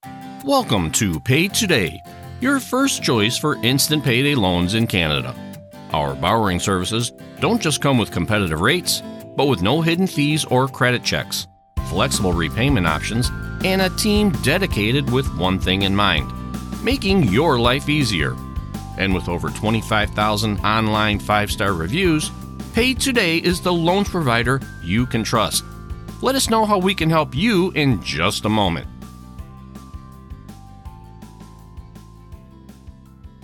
American Male Voice Over Artist
I work out of a broadcast quality home studio with professional recording equipment and a quick turnaround time!